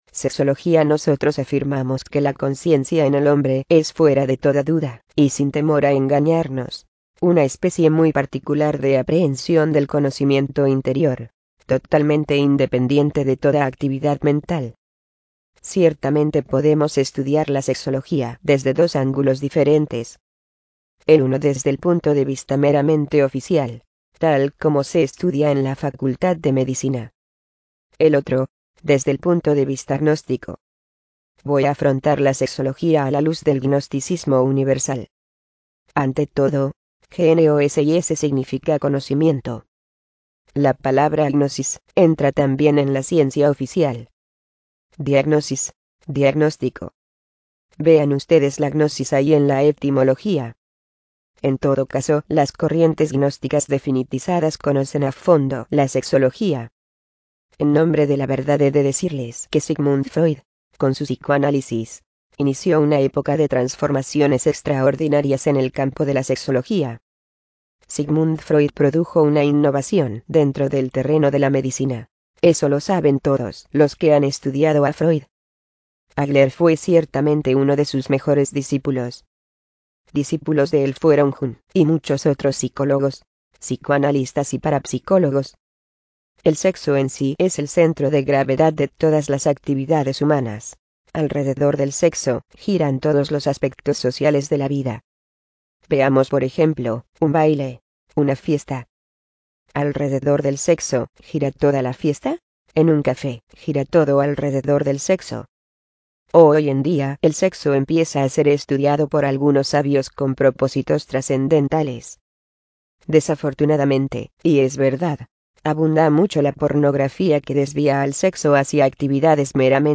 Audiolibros del maestro Samael Aun Weor